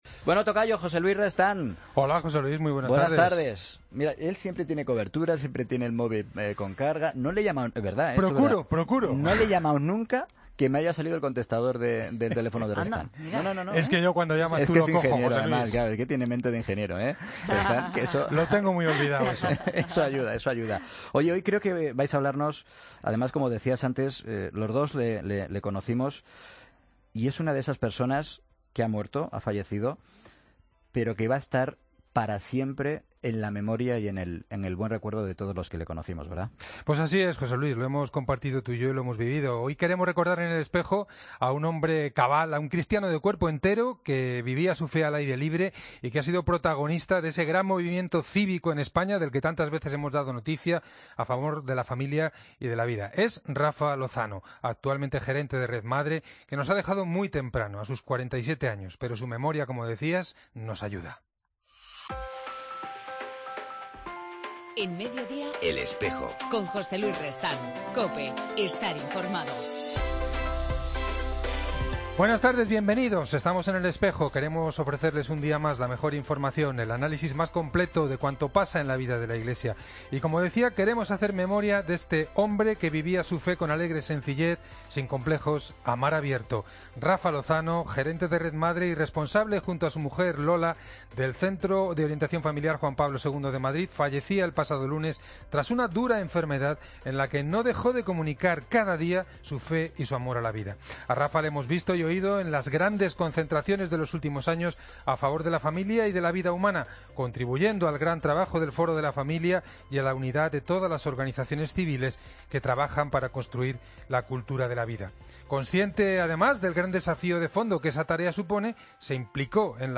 En El Espejo del 8 de septiembre entrevistamos a Aurelio García Macías